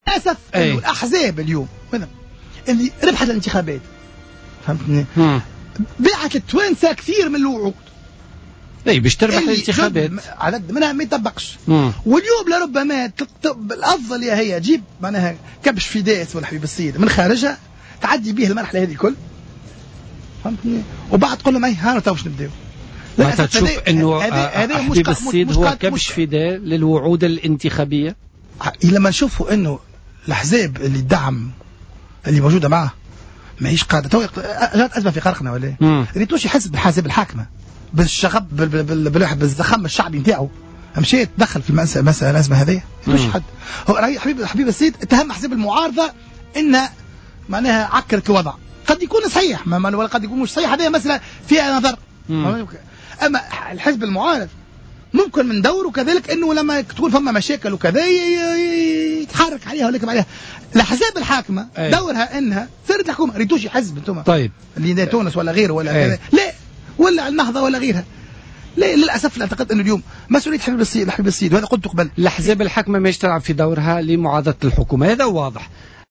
وأضاف بن غربية ضيف برنامج "بوليتيكا" اليوم الأربعاء أن الأحزاب المكونة للائتلاف الحاكم انغمست في مشاكلها الداخلية وتركت الحبيب الصيد في مواجهة الوعود "الخيالية" التي قطعتها خلال حملاتها الانتخابية.